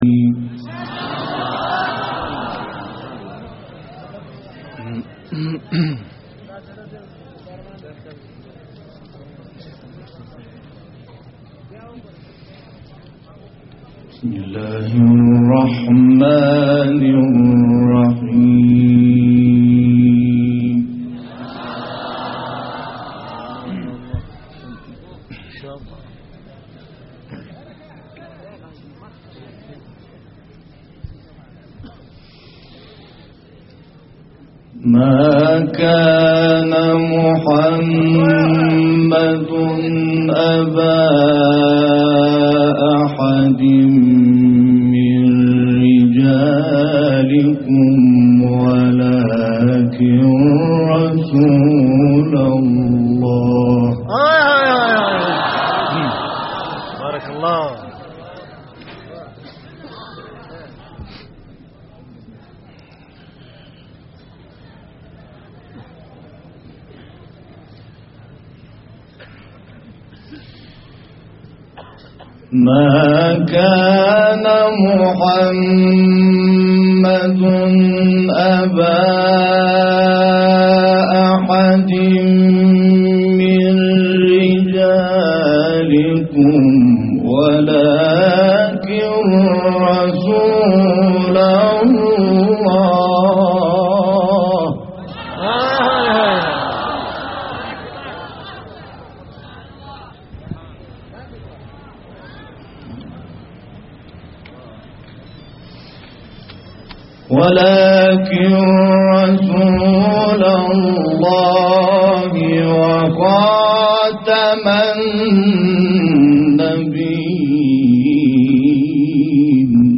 دانلود قرائت سوره احزاب آیات 40 تا 48، اعلی و شمس آیات 1 تا 5 - استاد محمود شحات